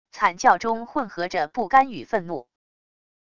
惨叫中混合着不甘与愤怒wav音频